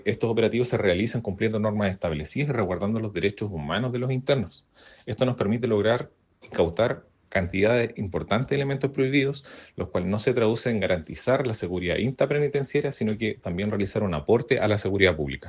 El Seremi de Justicia, Carlos Uslar, entregó más detalles del procedimiento.